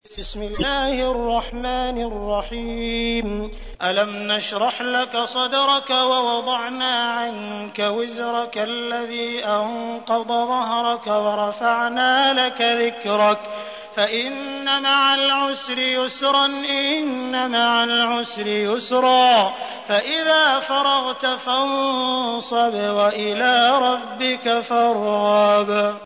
تحميل : 94. سورة الشرح / القارئ عبد الرحمن السديس / القرآن الكريم / موقع يا حسين